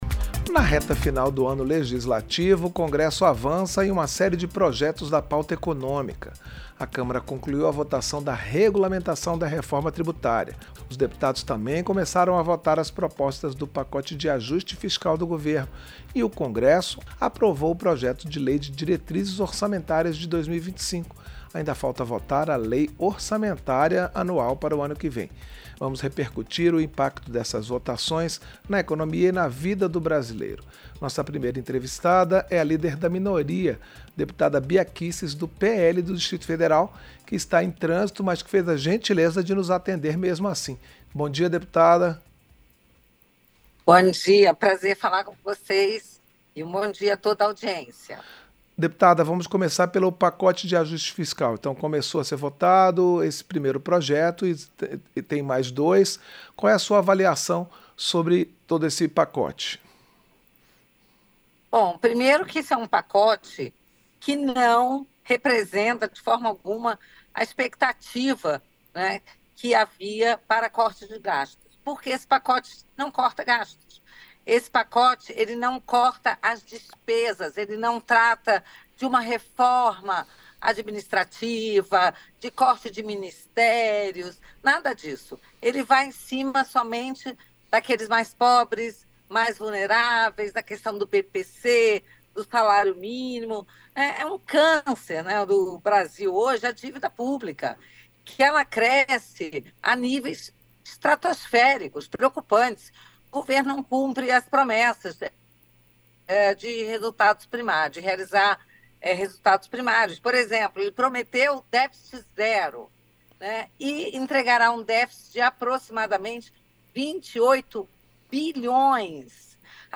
Entrevista - Dep. Bia Kicis (PL-DF)